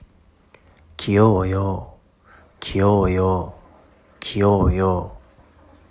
韓国語で「可愛いです」と言いたい場合は、귀여워요（キヨウォヨ）と発音します。